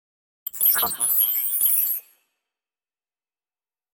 دانلود صدای ربات 62 از ساعد نیوز با لینک مستقیم و کیفیت بالا
جلوه های صوتی